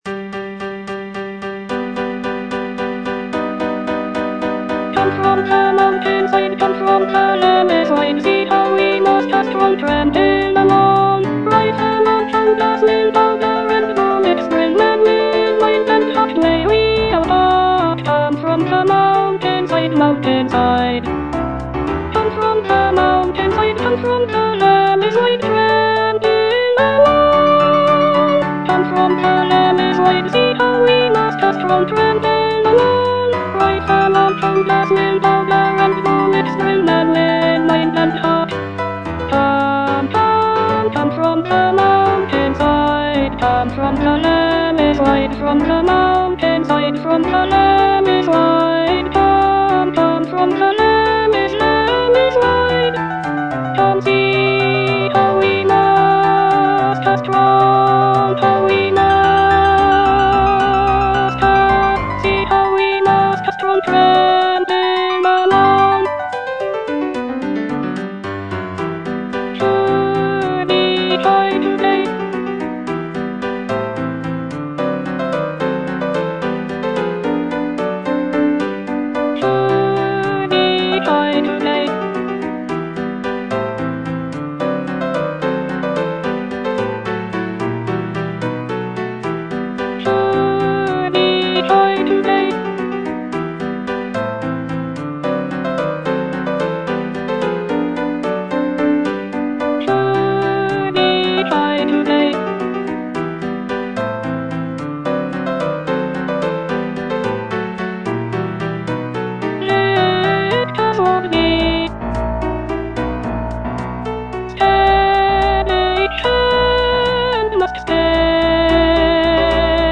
E. ELGAR - FROM THE BAVARIAN HIGHLANDS The marksmen (alto I) (Voice with metronome) Ads stop: auto-stop